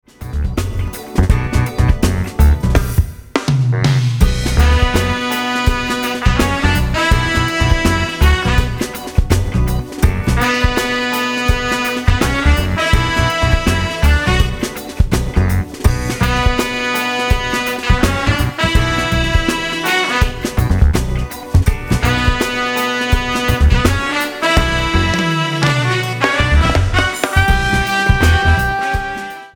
165 BPM